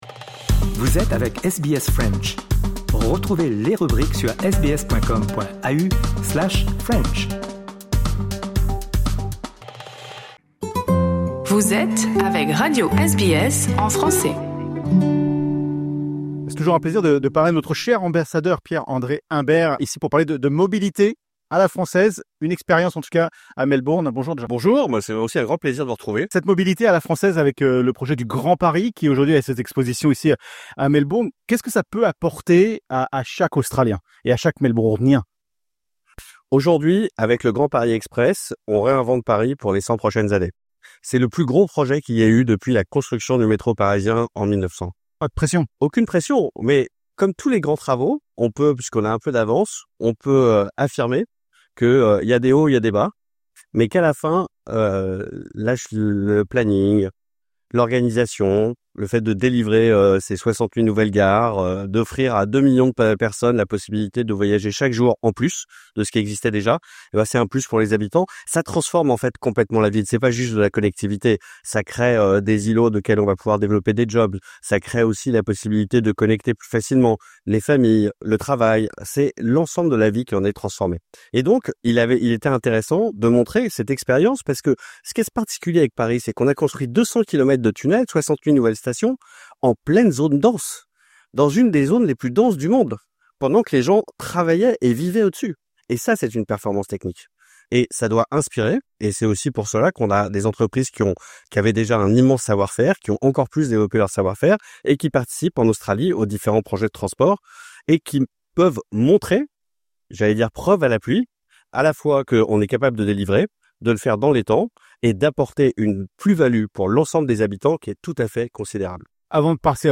Discussion avec Pierre-André Imbert, l'ambassadeur de France en Australie, sur ce que le Grand Paris Express peut apprendre à Melbourne et aux villes en pleine croissance.